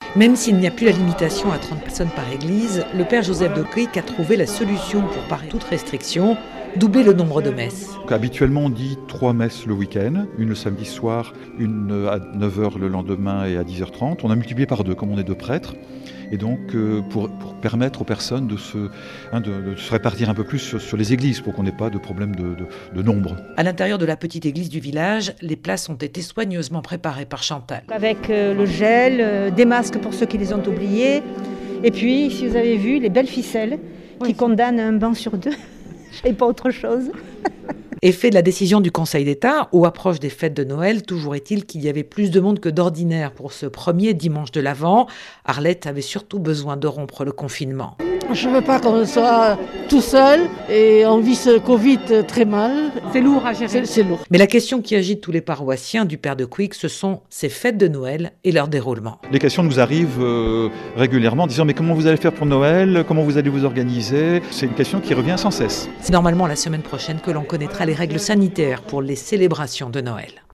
Reportage à Montgey dans le Tarn, village de 300 habitants